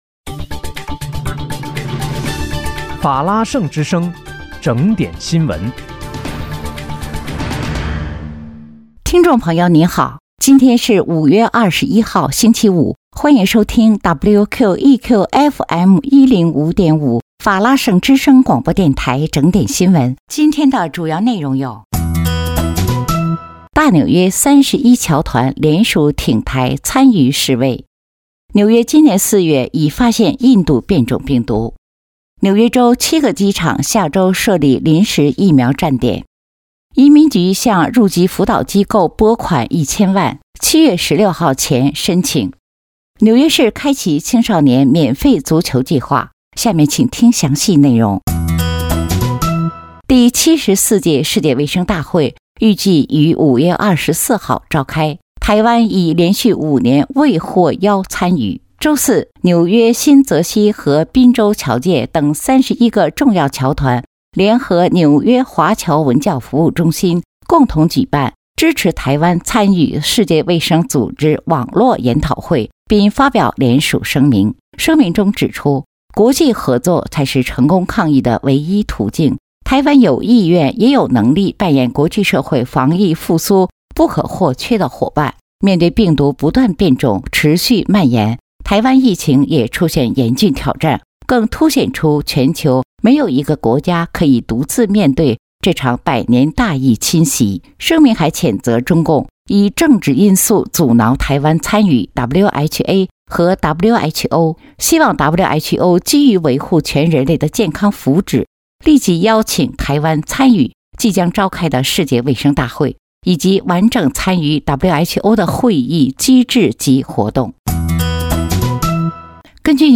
5月21日（星期五）纽约整点新闻